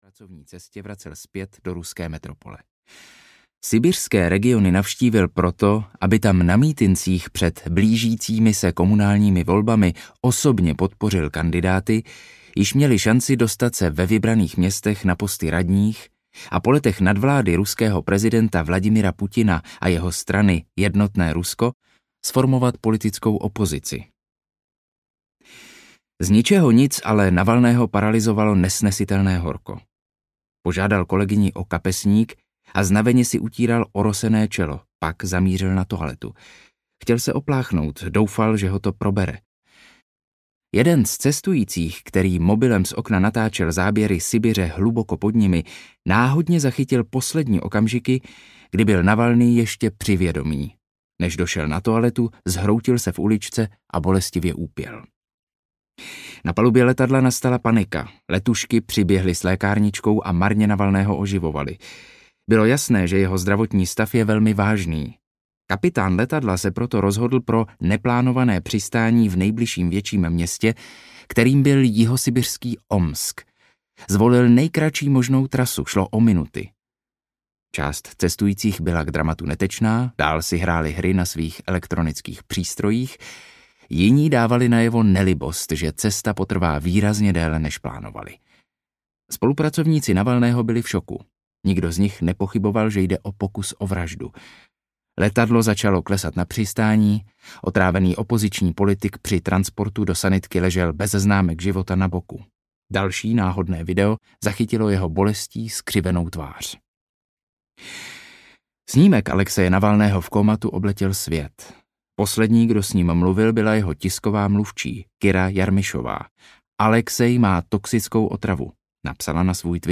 Audio knihaNovičok nebo kulka: Jak umírají Putinovi kritici
Ukázka z knihy